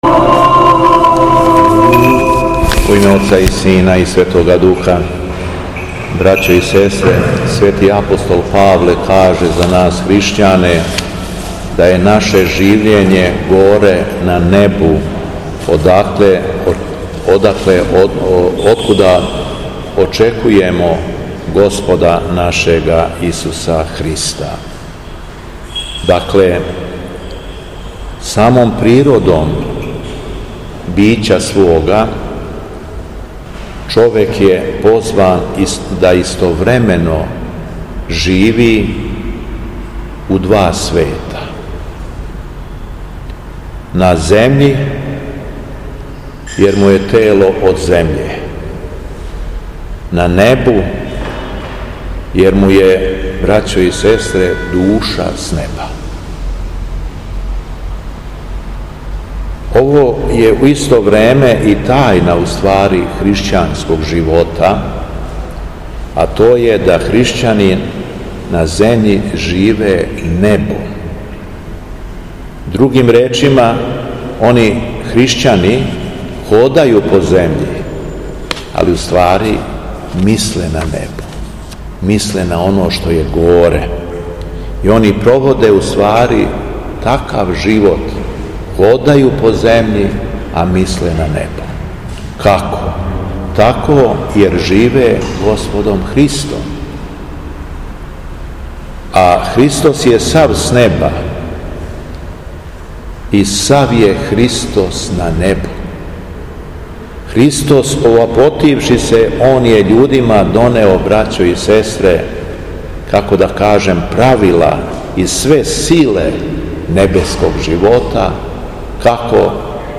Његово Високопреосвештенство Митрополит шумадијски Господин Јован служио је свету архијерејску литургију, у понедељак седми по Духовима, у храму Светога Саве у крагујевачком насељу Аеродром.
Беседа Његовог Високопреосвештенства Митрополита шумадијског г. Јована